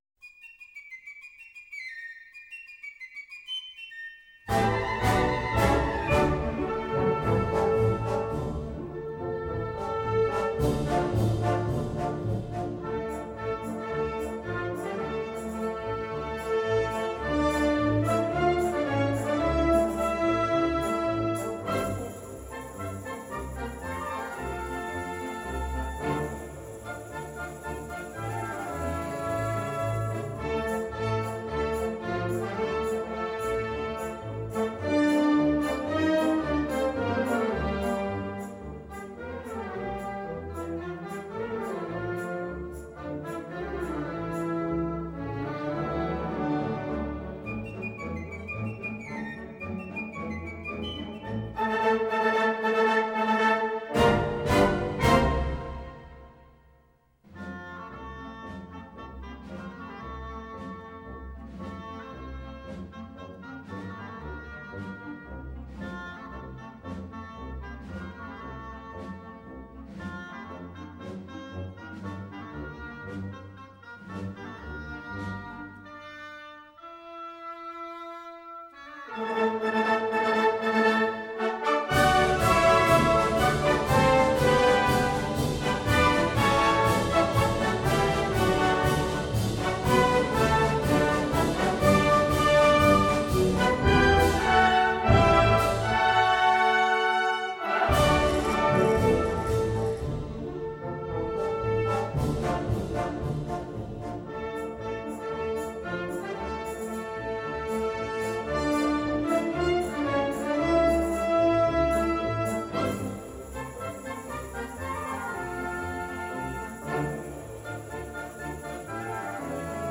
sobre verde. pasodoble. banda madrid. 1995.mp3